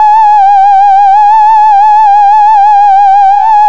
Index of /90_sSampleCDs/USB Soundscan vol.03 - Pure Electro [AKAI] 1CD/Partition D/06-MISC
SCREAM SYN-R.wav